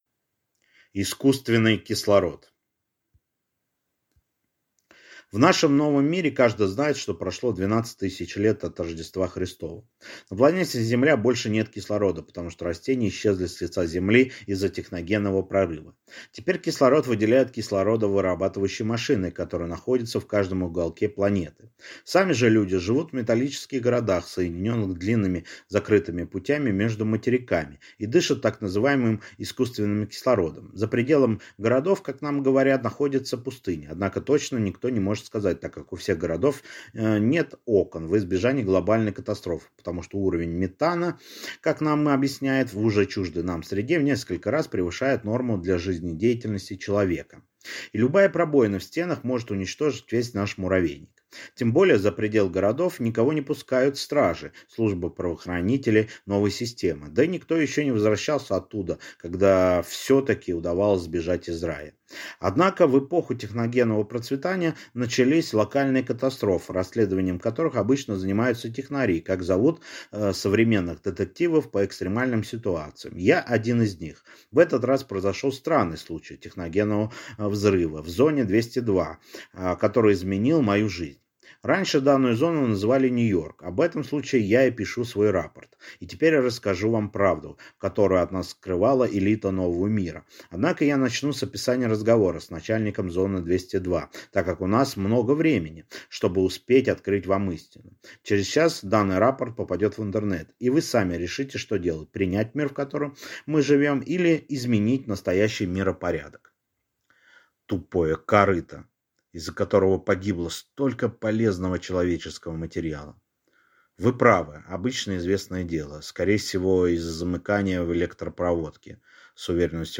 Аудиокнига Искусственный кислород | Библиотека аудиокниг